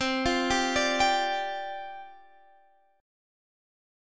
Csus2sus4 chord